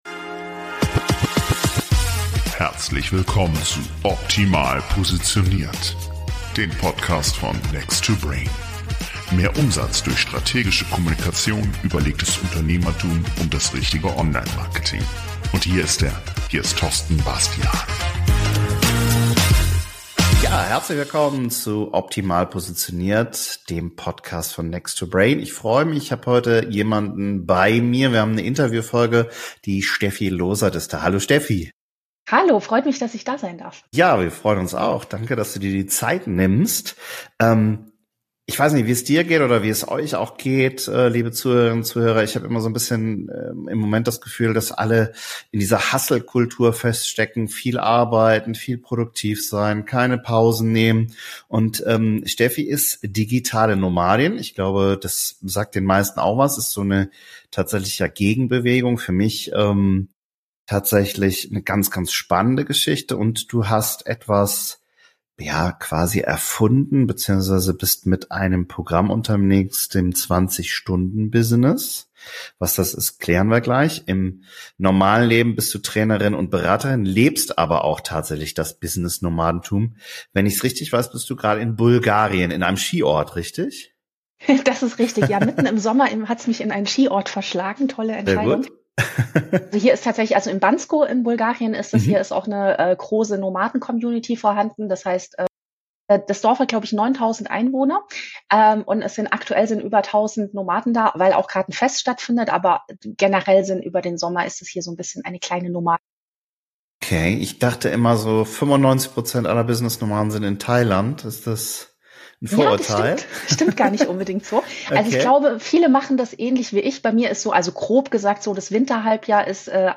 Interview-Folge